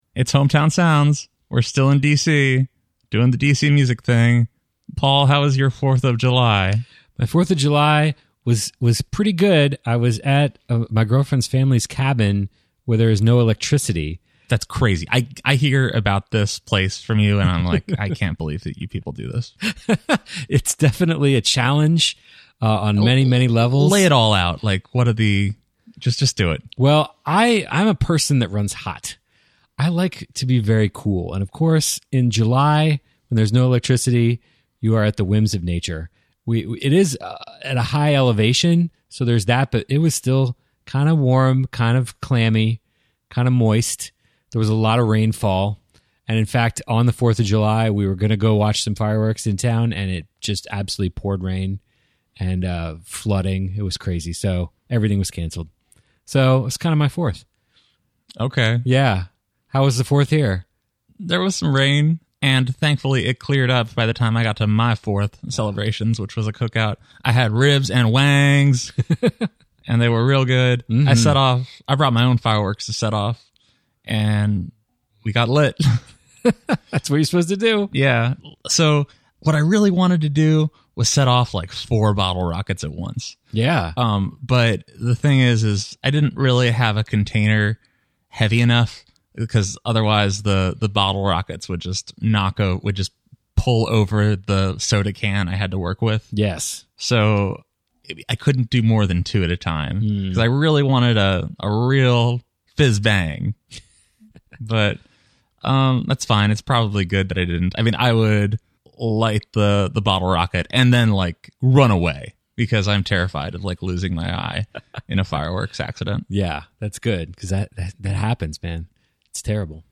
In addition to recapping their Halloween outings, they serve up the great new songs from DC that you demand!